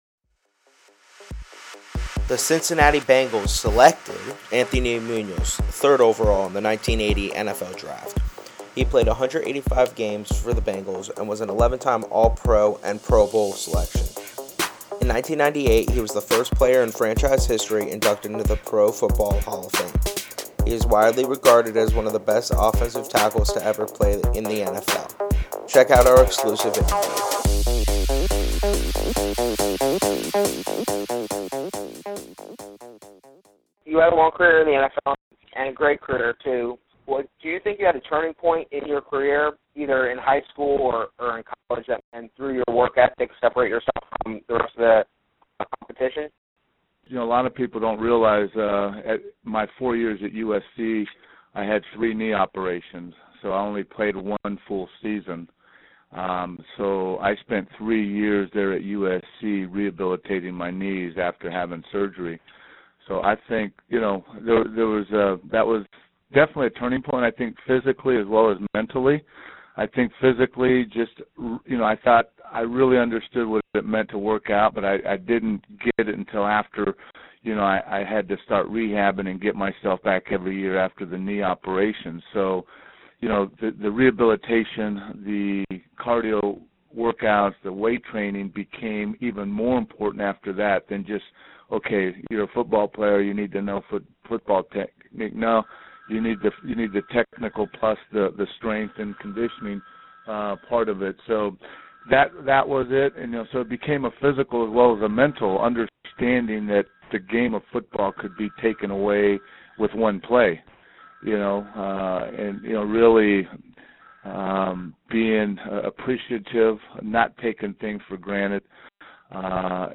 Bengals Hall of Fame OT, Anthony Muñoz Interview
anthony-munoz-interview.mp3